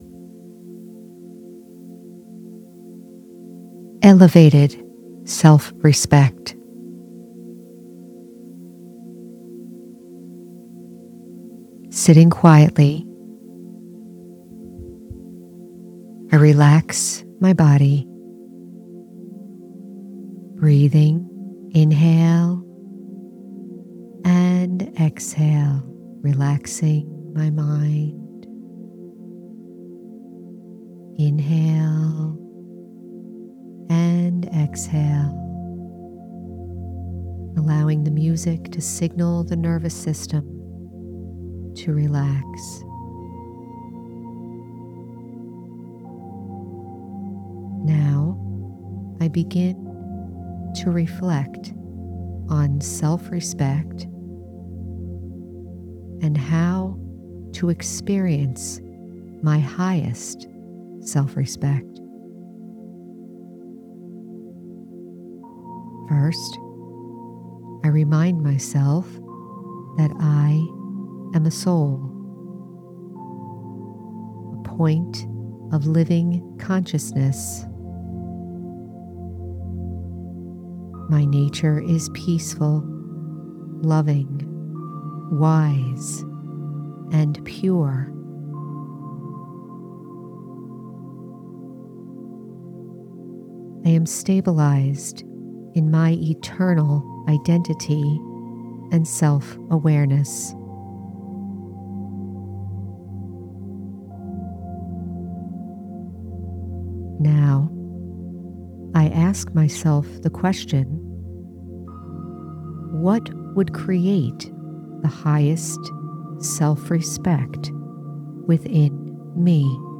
Elevated Self- Respect- Guided Meditation- The Spiritual American- Episode 172